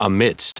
Prononciation du mot amidst en anglais (fichier audio)
Prononciation du mot : amidst